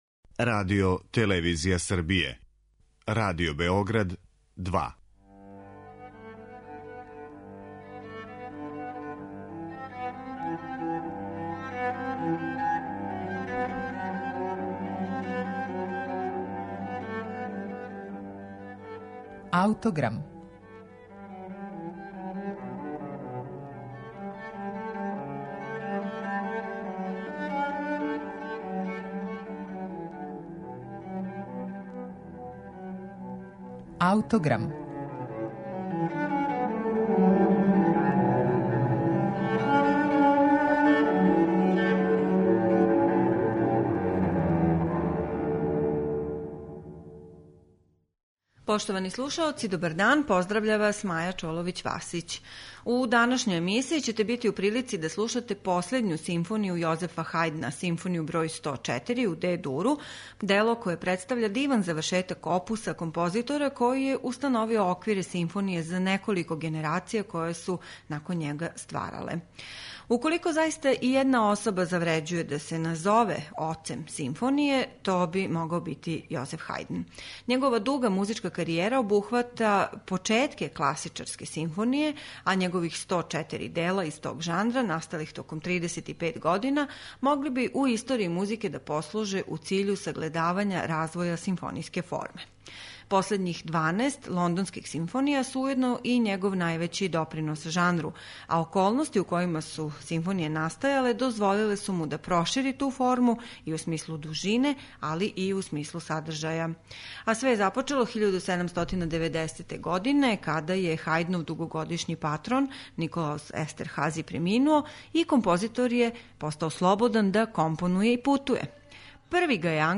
Данас ћете је слушати у извођењу Новог филхармонија оркестра, којим диригује Ото Клемперер.